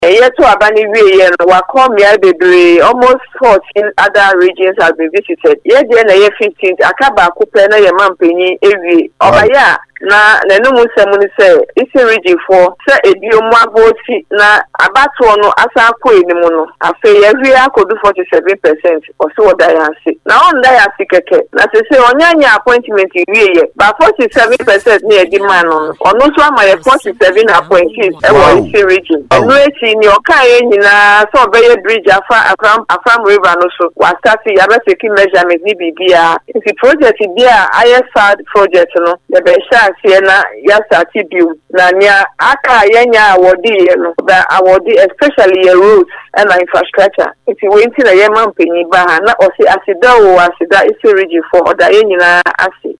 Speaking on Radio1’s morning show, the minister outlined the key messages delivered by the former president during his engagements with chiefs, opinion leaders, and party supporters.